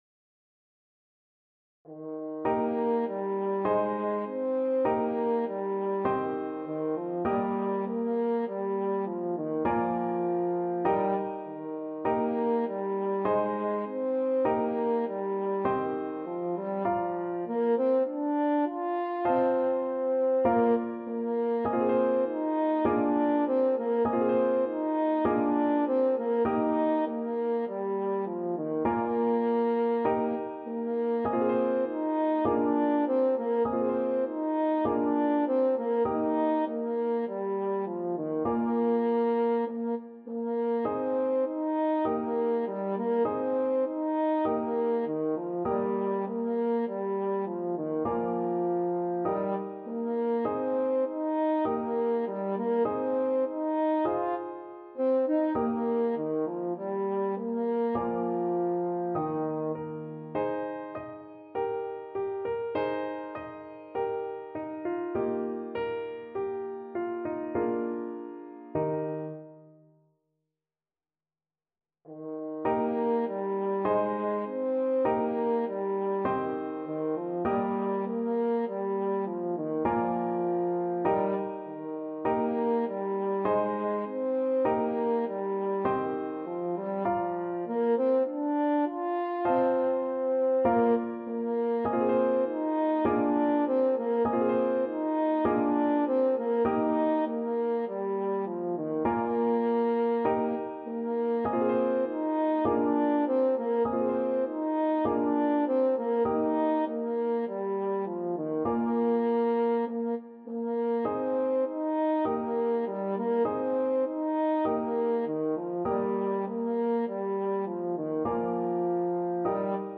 Moderato
Classical (View more Classical French Horn Music)